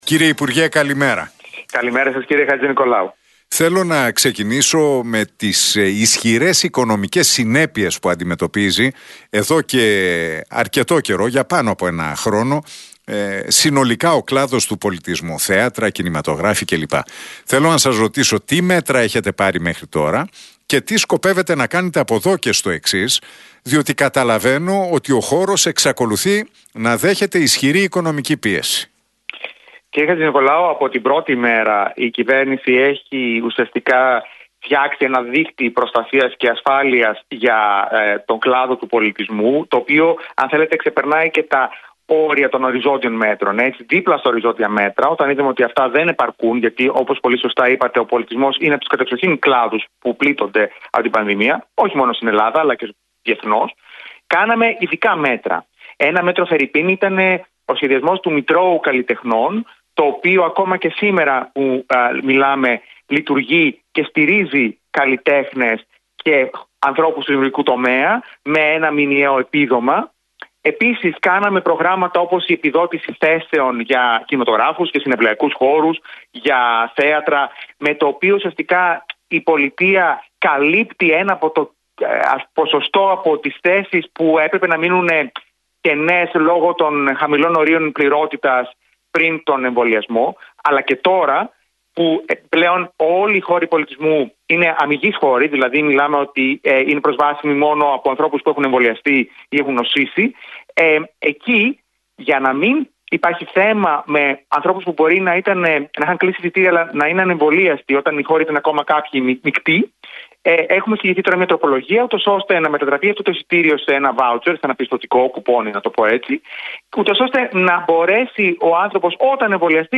Ο Νικόλας Γιατρομανωλάκης αναφέρθηκε στα μέτρα στήριξης για τον κλάδο του πολιτισμού σε συνέντευξή του στον Realfm 97,8 και στην εκπομπή του Νίκου Χατζηνικολάου.